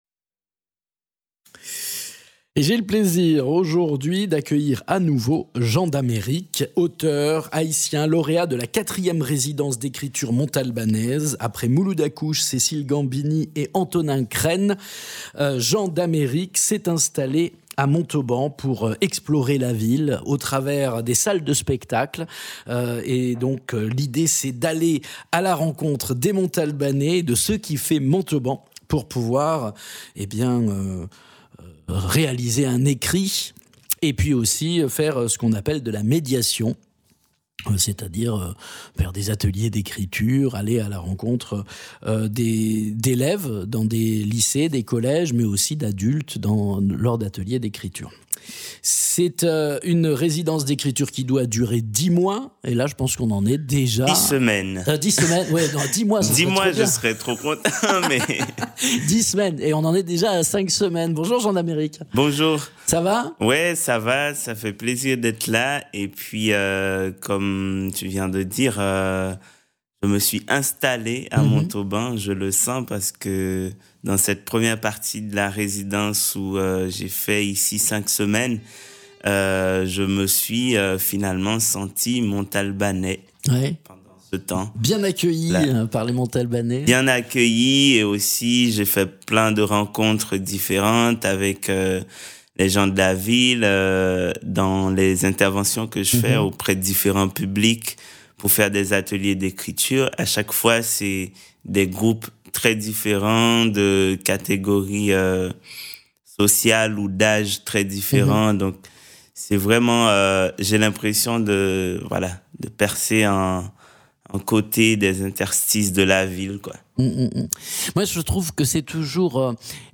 Interviews
Invité(s) : Jean d’Amérique, auteur haïtien